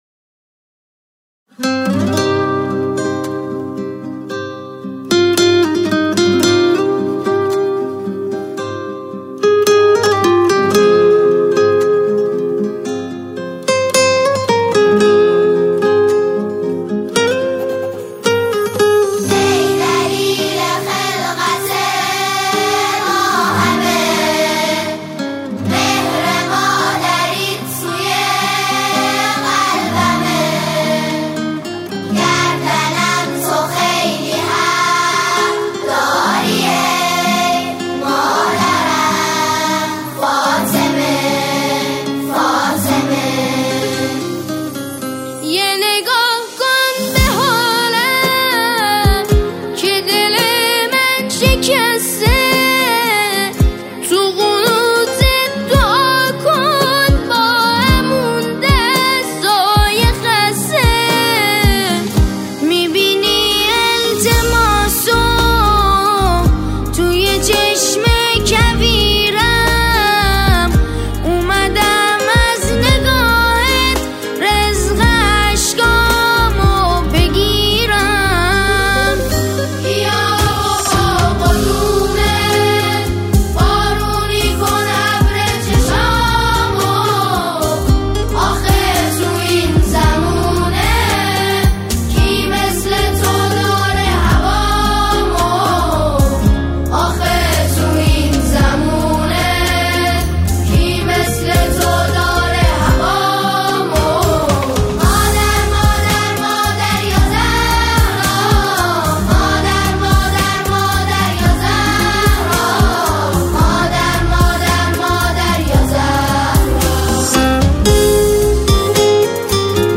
این قطعه را با حال‌وهوایی حزن‌آلود و سوگوارانه اجرا کرده‌اند
ژانر: سرود